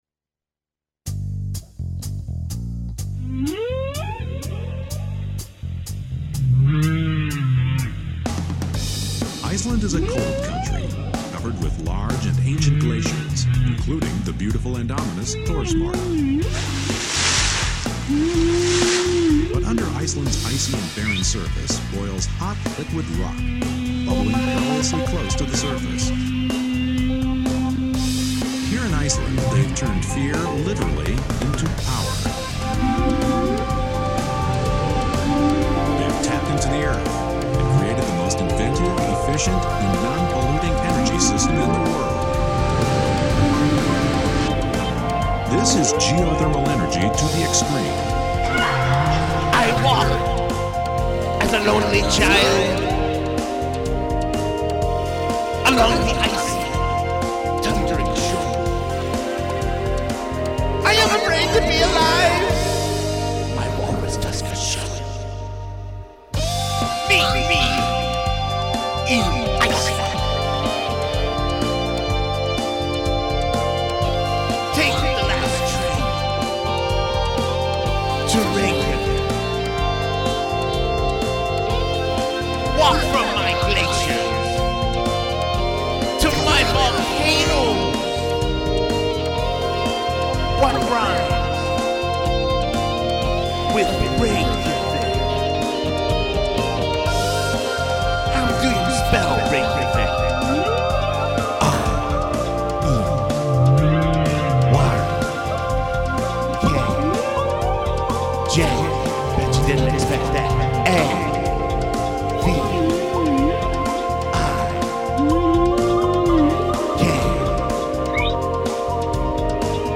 We then went out and found samples to go with it.